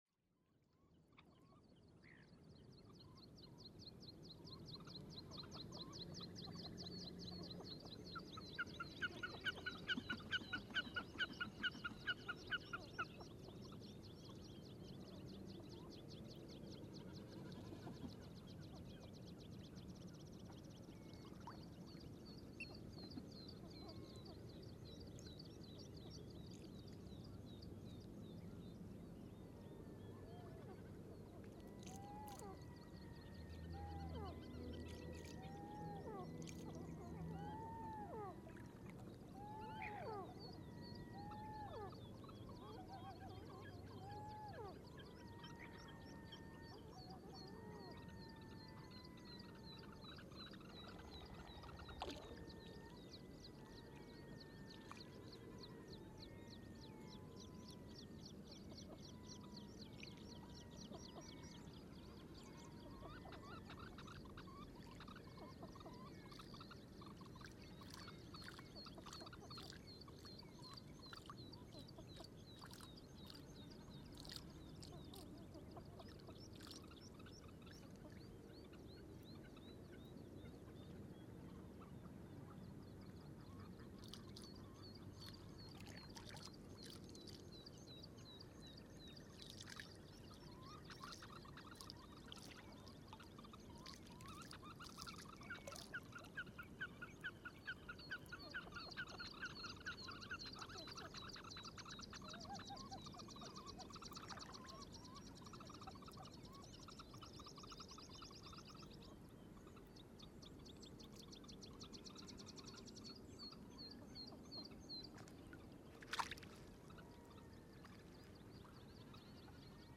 Við það gerðist það undraverða að fuglar úr vissum áttum hljómuðu í einhverju bergmáli.
Posted in Náttúra, tagged Birds, Friðland í Flóa, Friðland í Flóa 2012, Fuglar, Iceland, Lómur, Nature reserve, NOS, Rode NT1a, Sound Devices 744 on 9.6.2013| Leave a Comment »
Day after, during the night and early morning at 26th I continued to record, but now I moved the microphones about 3-5 meter closer to nearby pond.
There was a strange echo or reverb in some directions.
As usual this recording are made in virtually quiet environment.
It is mostly very quiet, but in the end a choir of Red throated Diver gets very loud.